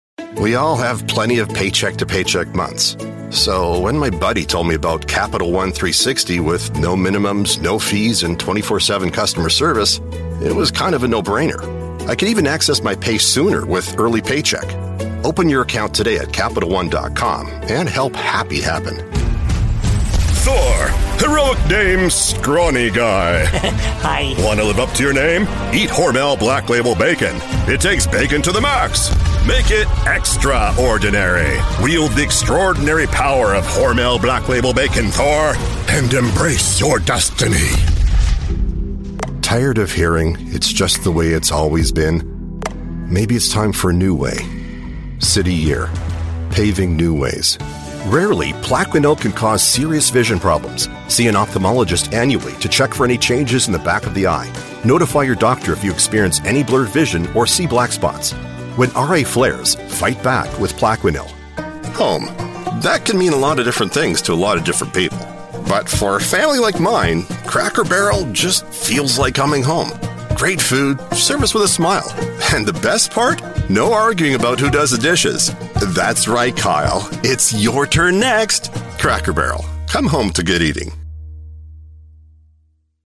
Englisch (Kanadisch)
Freundlich
Konversation